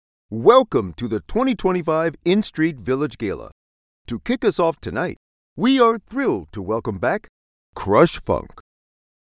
Warm, wise and comforting.
Voice of God Style
General American, African American, Western, South and New York accents.
Middle Aged